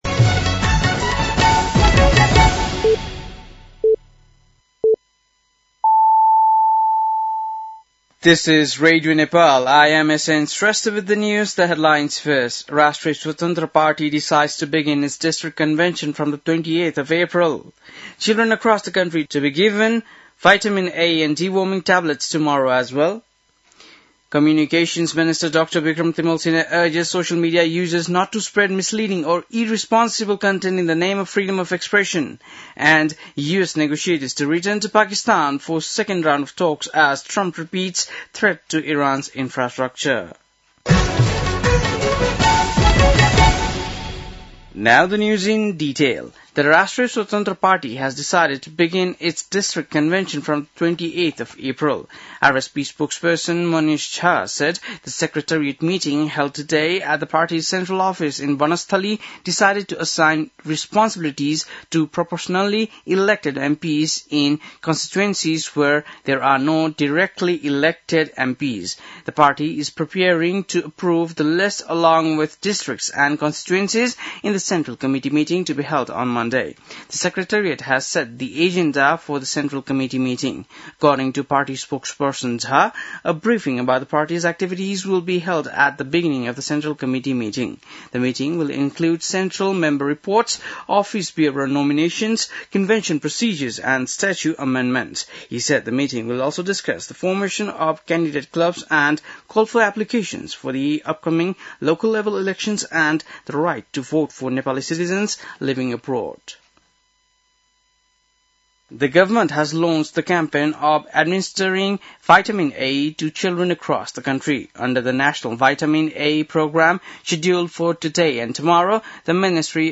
बेलुकी ८ बजेको अङ्ग्रेजी समाचार : ६ वैशाख , २०८३
8.-pm-english-news-1-2.mp3